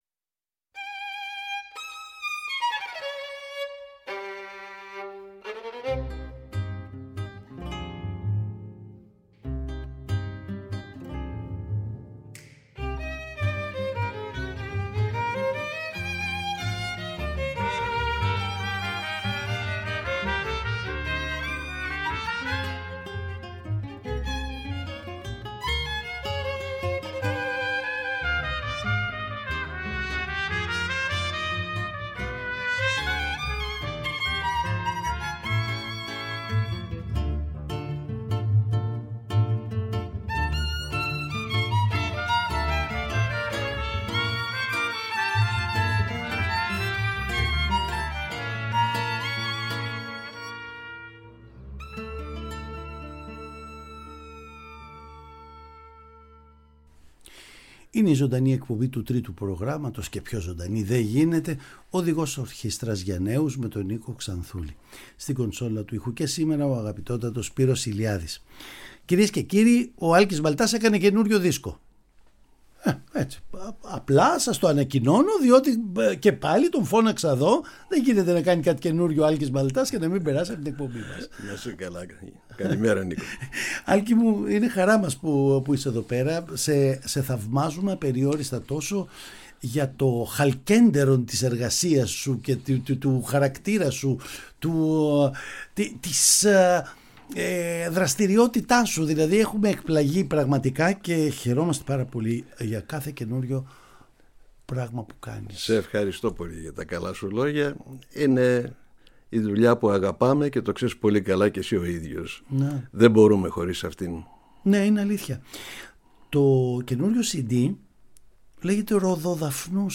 Το παραδοσιακό παραμύθι «Ροδοδαφνούσα» παρουσιάζεται με έναν μοναδικό τρόπο με αφηγητή τον ίδιο τον συνθέτη και πλαισιωμένο από 14 τραγούδια για γυναικεία χορωδία α καπέλα. Όλες οι μελωδίες –με εξαίρεση τον πρόλογο και τον επίλογο του παραμυθιού– είναι παραδοσιακές από διάφορα μέρη της Ελλάδας (Νίσυρο, Καστελόριζο, Κάρπαθο, Κέρκυρα κ.α.).